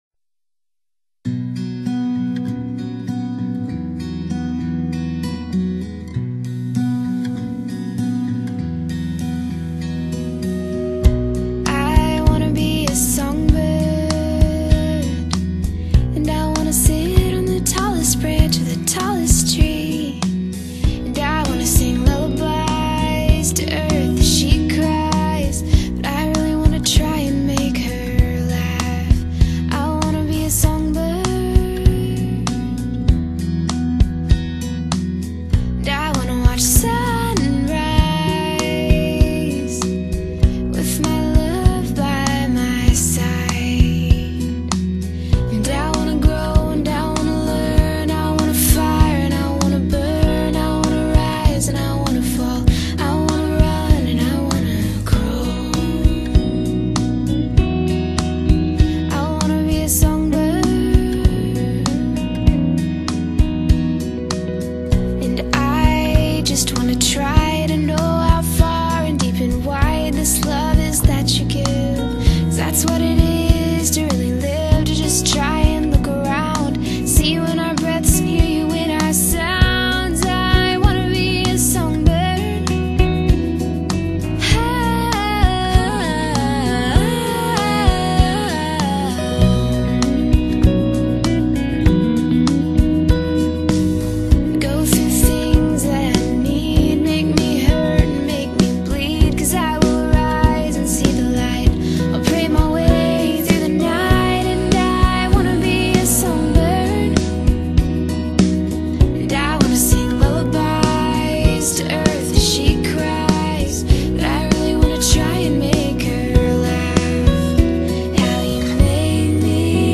音乐风格: 民谣
轻盈和质朴几乎是所有女声民谣都具备的特质，这张专辑自然也不例外。
轻盈、质朴的女声，Good！